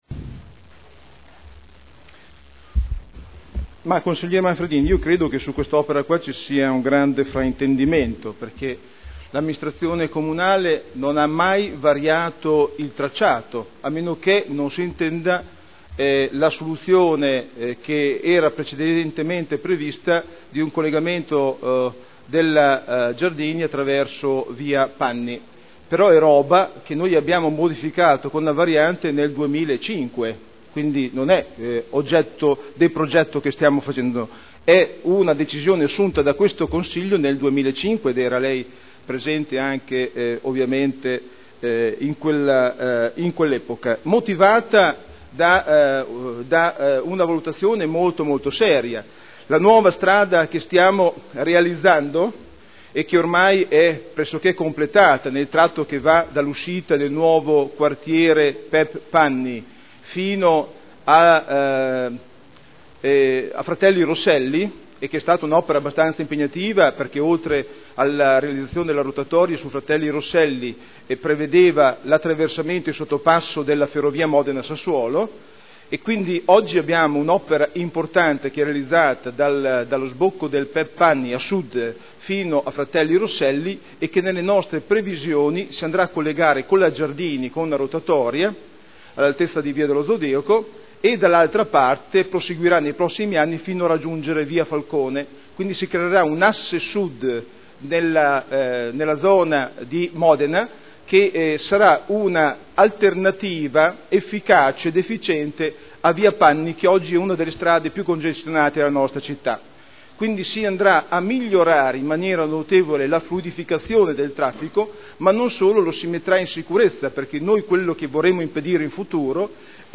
Seduta del 11/11/2010. Risponde a interrogazione del consigliere Manfredini (Lega Nord) su strada vicino alla Chiesa di Saliceta San Giuliano